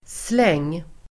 Uttal: [sleng:]